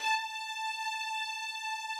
strings_069.wav